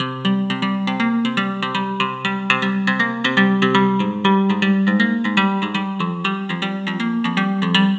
Like Guitar Lead.wav